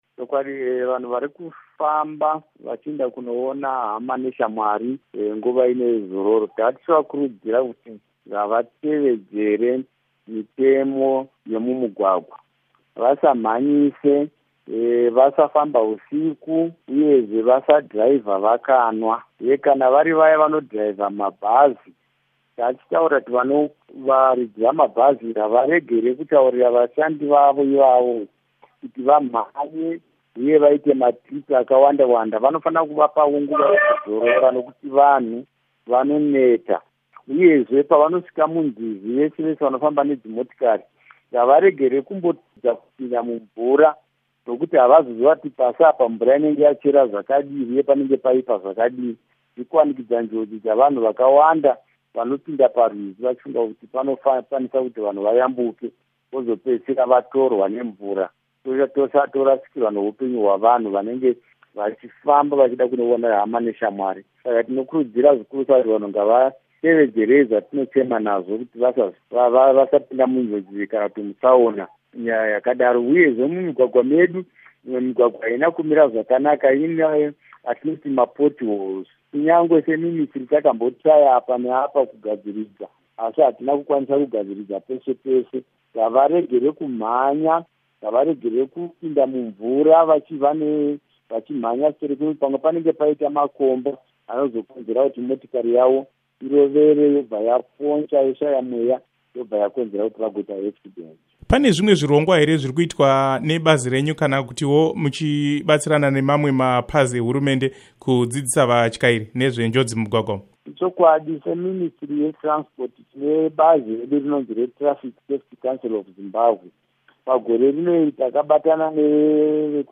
Hurukuro naVaJoram Gumbo